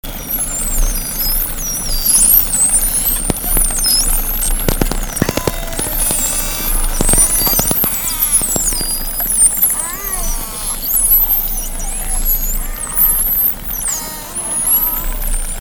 Ήχοι Δελφινιών
Ήχοι από υδρόφωνο: 1
Ήχοι από κοπάδι
ζωνοδέλφινων & κοινών δελφινιών
StripedCommonDolphinGroup.mp3